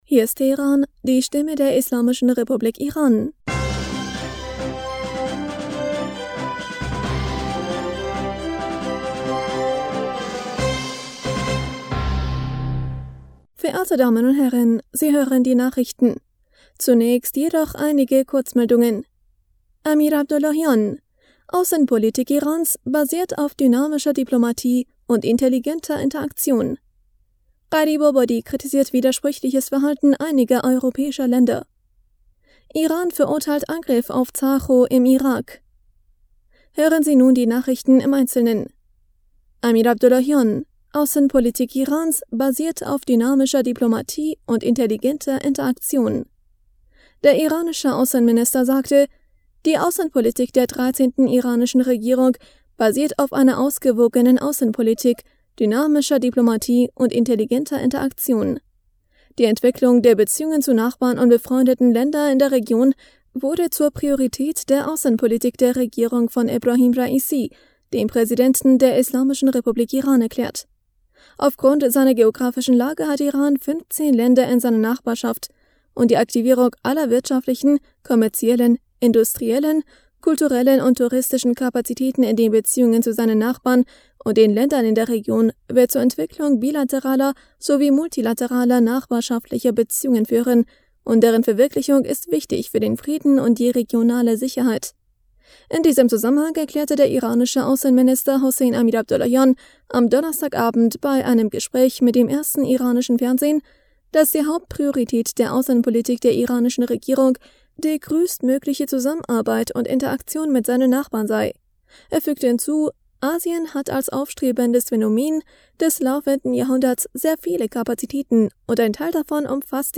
Nachrichten vom 22. Juli 2022